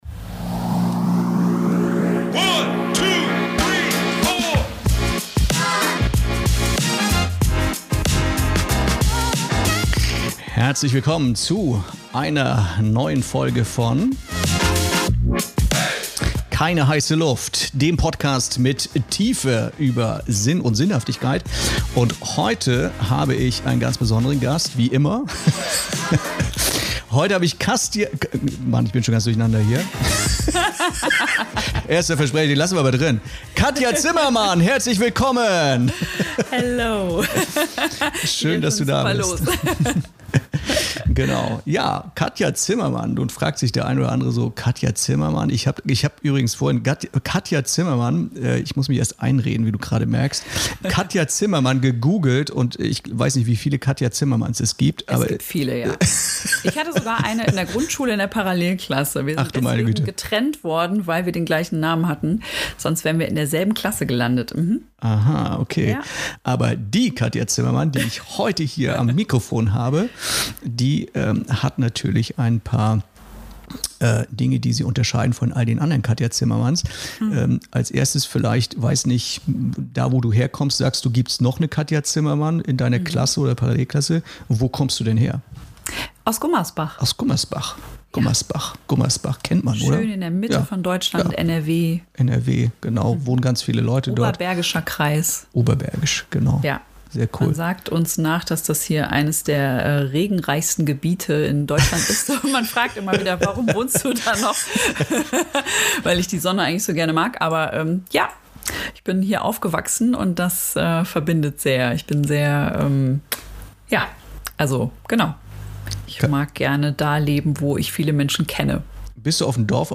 Keine heiße Luft beleuchtet in jeder Folge Themen, die uns herausfordern und inspirieren, und dieses Gespräch ist keine Ausnahme.